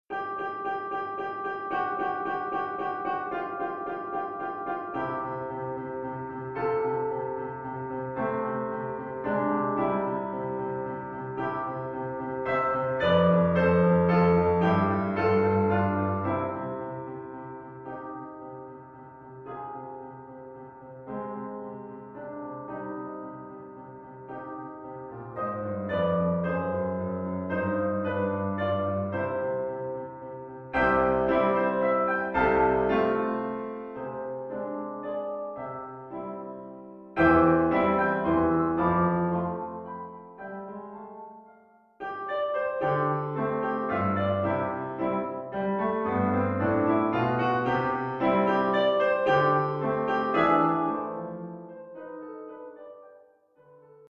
Oeuvre pour piano solo.